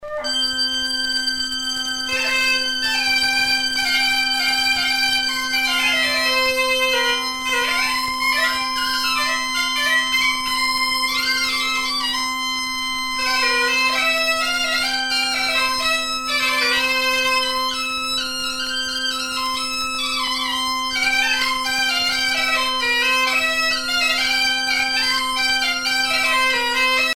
danse : jabadao
Pièce musicale éditée